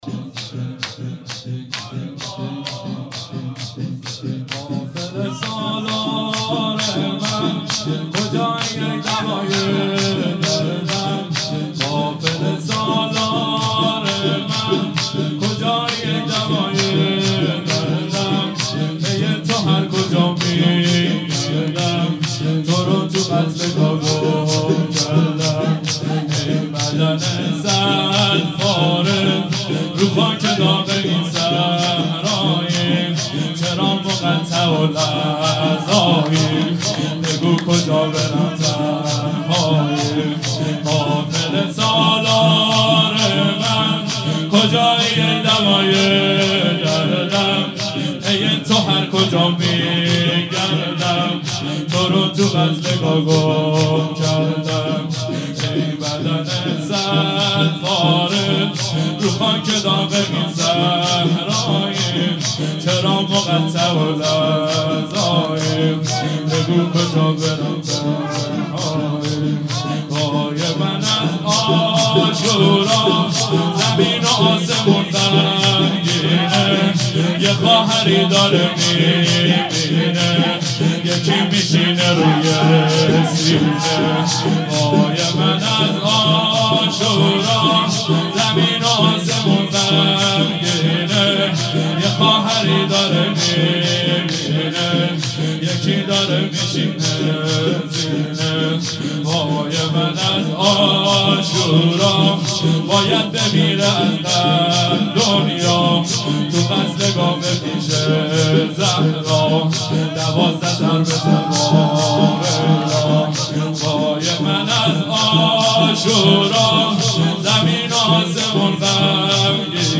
قافله سالار من شب عاشورا 96